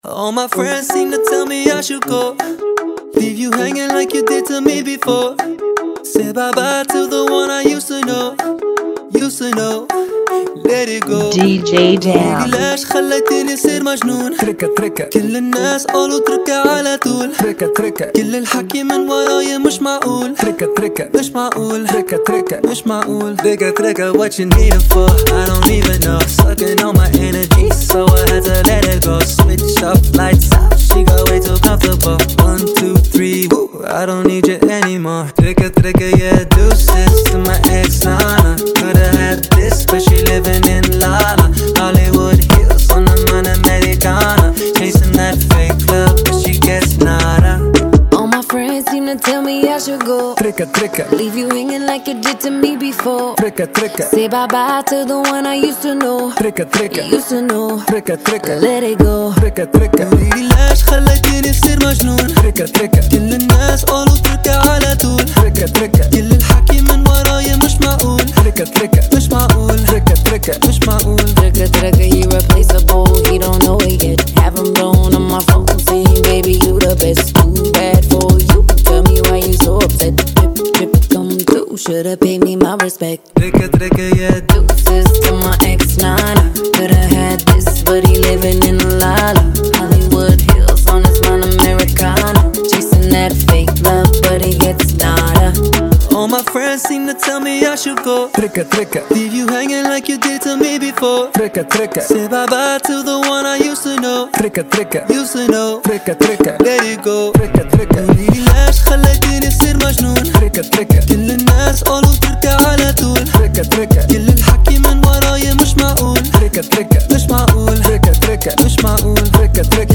160 BPM
Genre: Bachata Remix